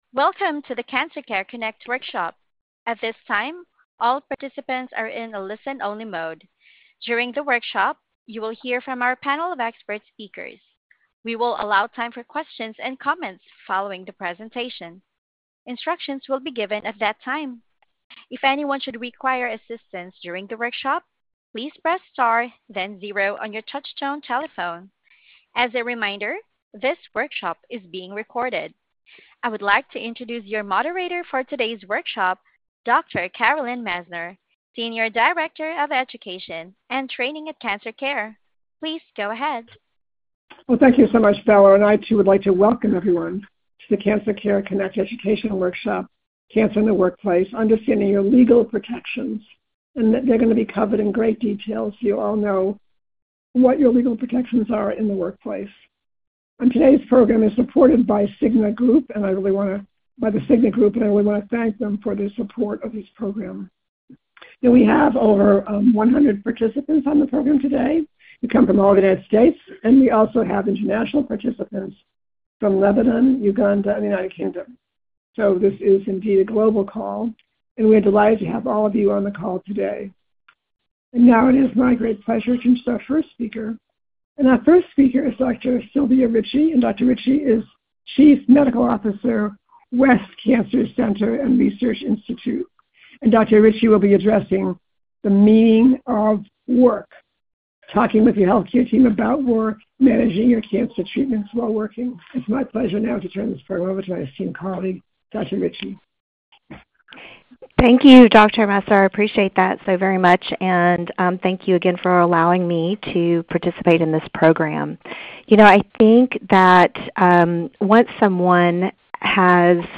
Questions for Our Panel of Expert